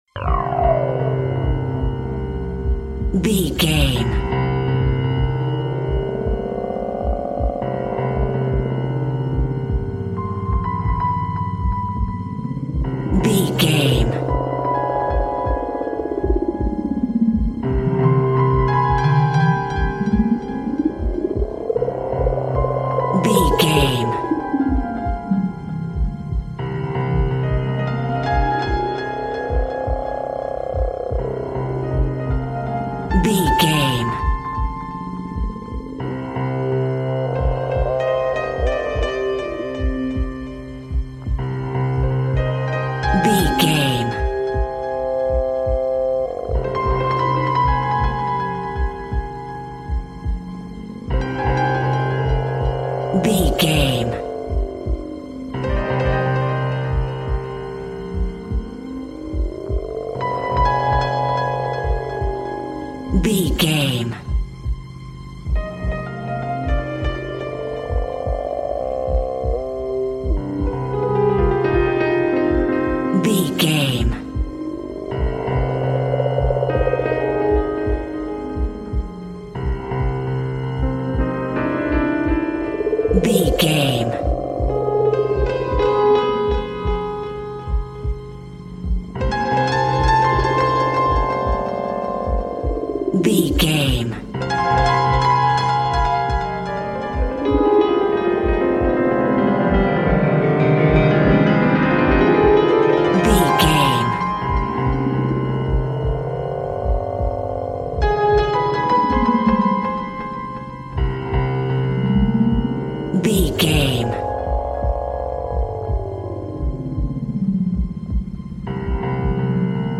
Alleyway Thriller Music.
In-crescendo
Thriller
Aeolian/Minor
ominous
suspense
eerie
strings
synth
ambience
pads